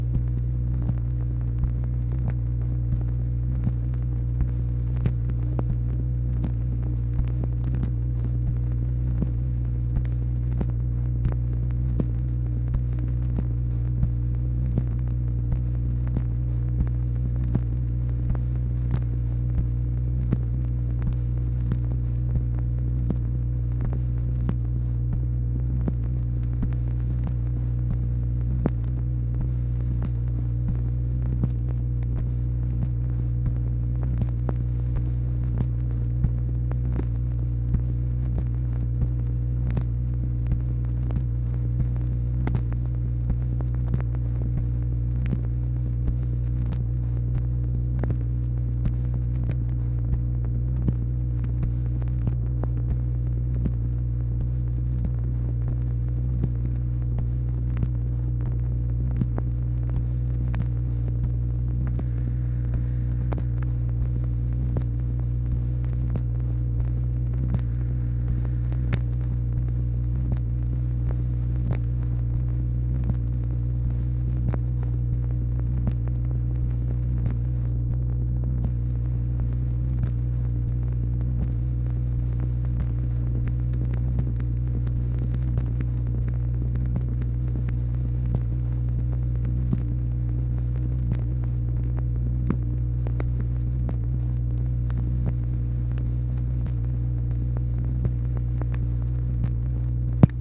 MACHINE NOISE, January 1, 1964